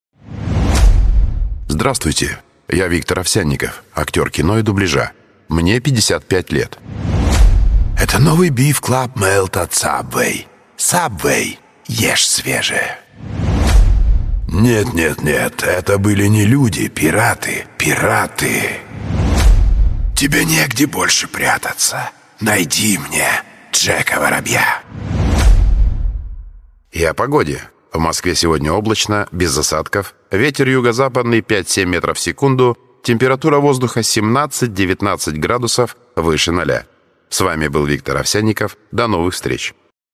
Мужской
Баритон Бас